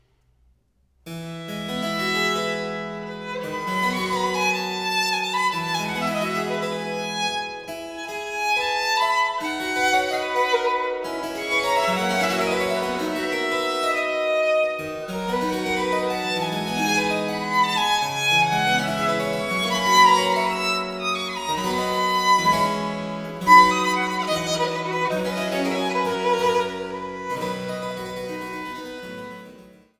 Die wohl bekanntesten Violinsonaten des 18. Jahrhunderts